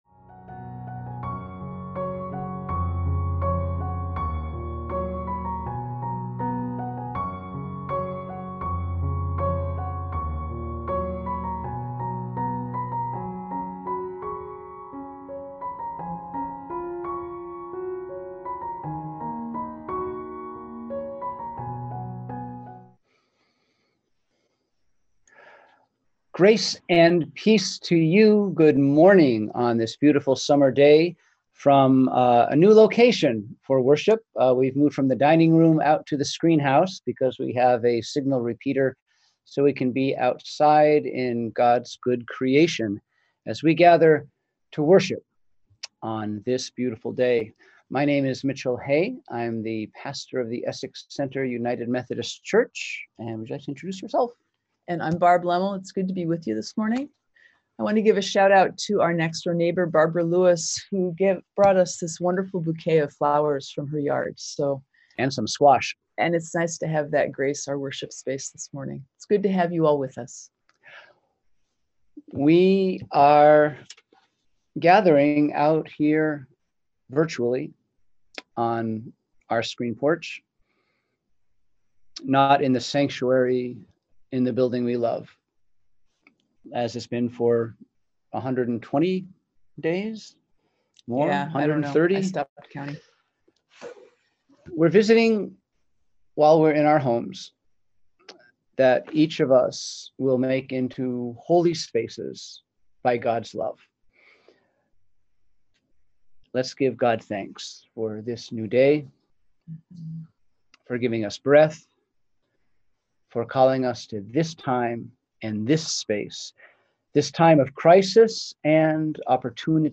We held virtual worship on Sunday, July 26, 2020 at 10am.